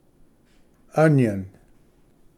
ᏒᎩ Pronunciation